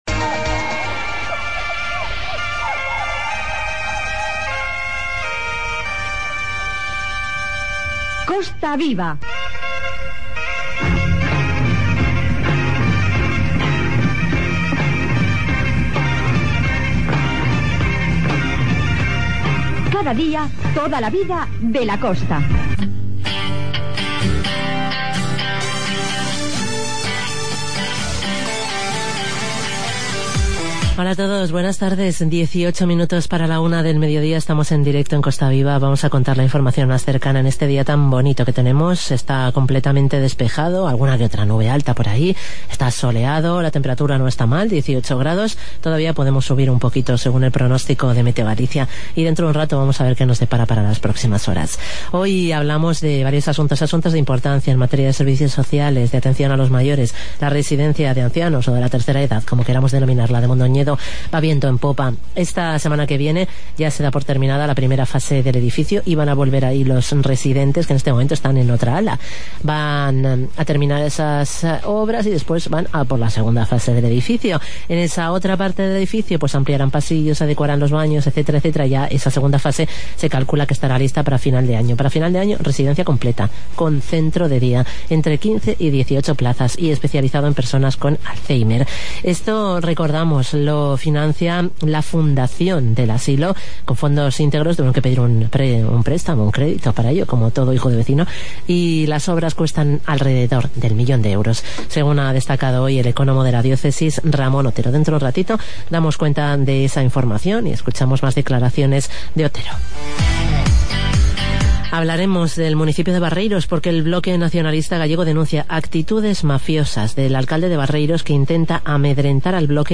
Informativo "Costa Viva"